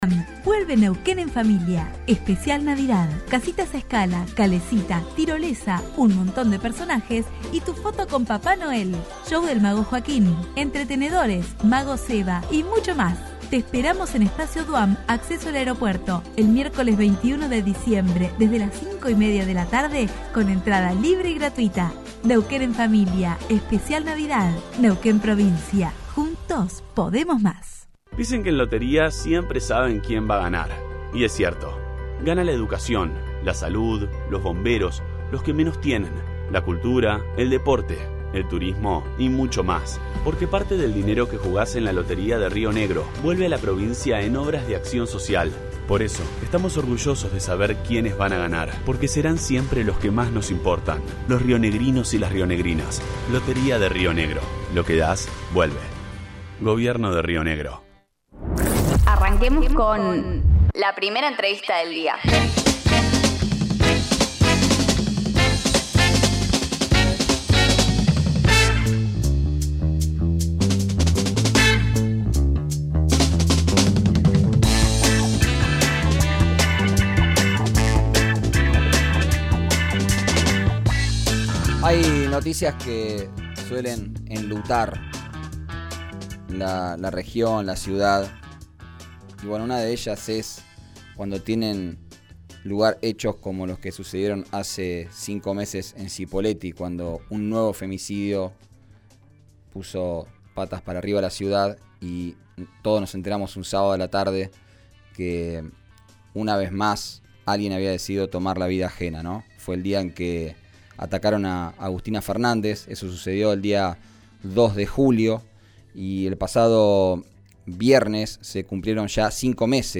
dialogó por RN Radio sobre cómo avanza la causa.